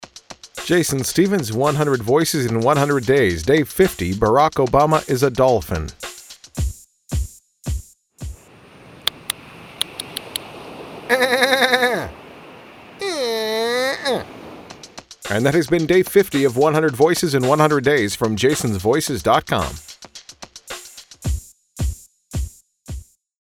My Barack Obama impression.
Tags: Barack Obama impression, celebrity voice overs, voice matching